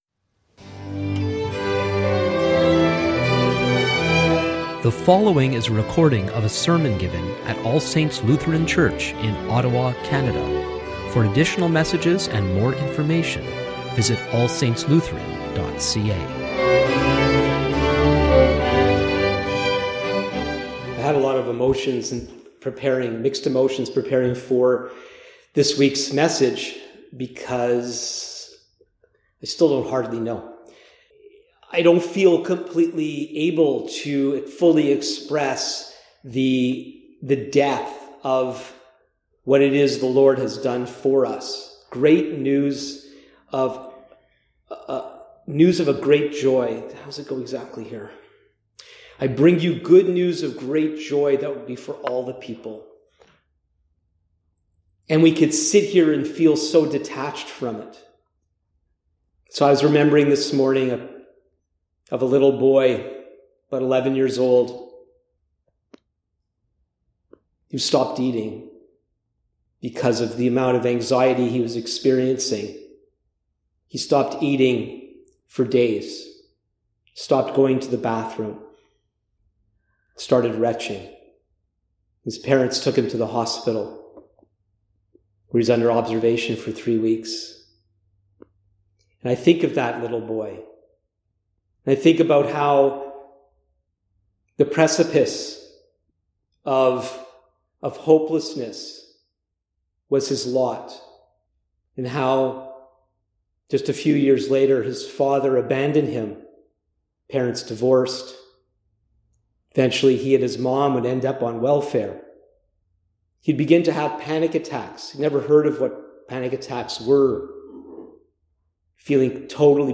Sermons | All Saints Lutheran Church